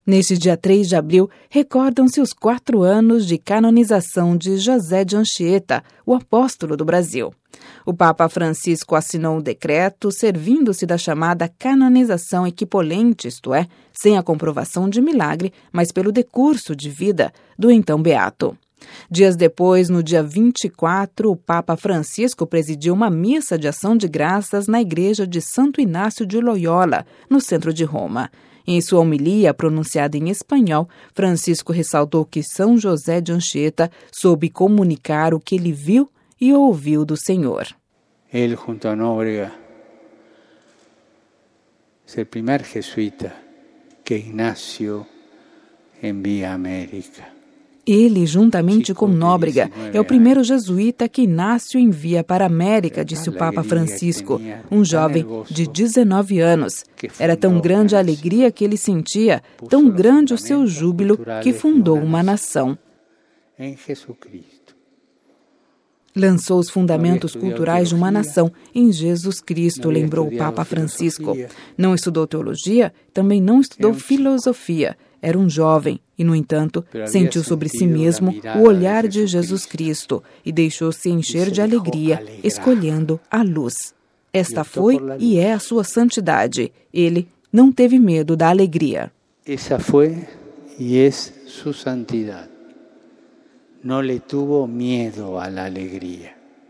Em sua homilia, pronunciada em espanhol, Francisco ressaltou que São José de Anchieta soube comunicar o que ele viu e ouviu do Senhor.
Ouça a reportagem com a voz do Papa Francisco